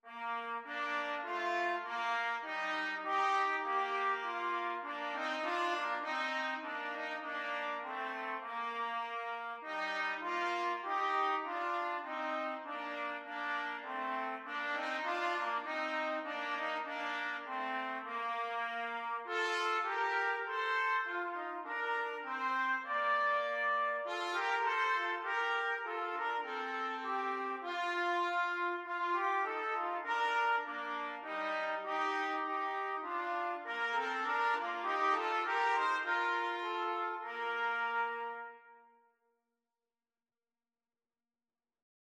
Free Sheet music for Trumpet Duet
Bb major (Sounding Pitch) C major (Trumpet in Bb) (View more Bb major Music for Trumpet Duet )
4/4 (View more 4/4 Music)
Trumpet Duet  (View more Easy Trumpet Duet Music)